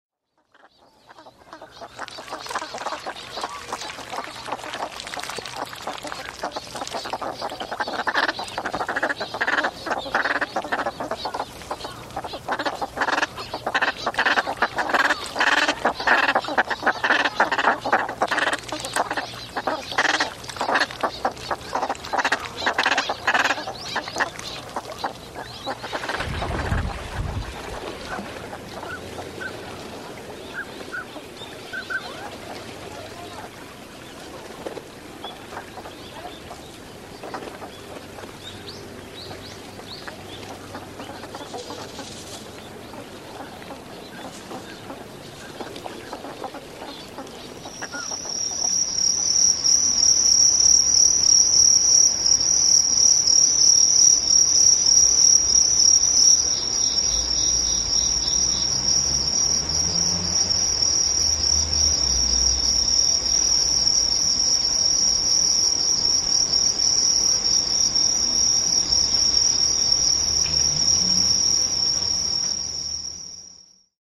A field recording album
• Genre: Experimental